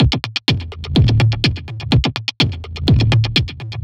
tx_perc_125_trashed.wav